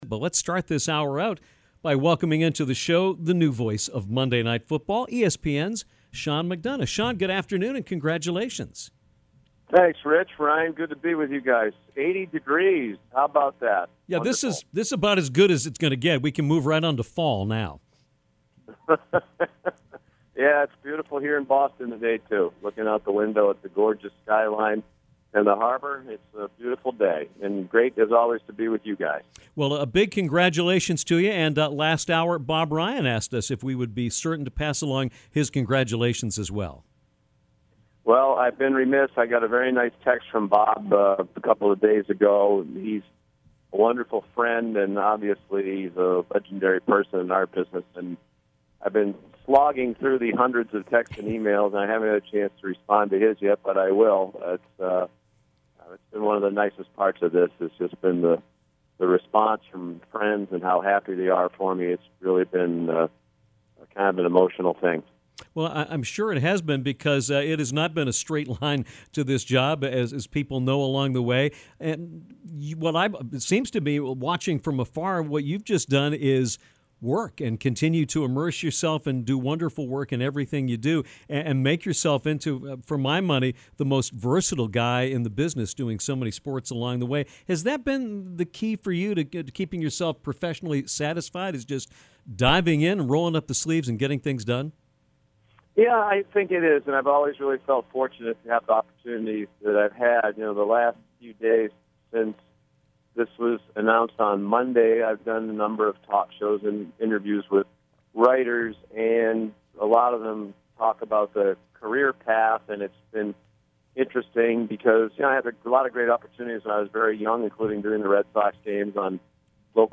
Sean McDonough, one the best in the business, took time to call Downtown fresh off of the announcement of his hiring as the play-by-play man for Monday Night Football to […]